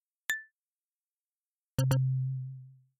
Correct and Incorrect Chime
bell chime chimes correct ding incorrect quiz tone sound effect free sound royalty free Sound Effects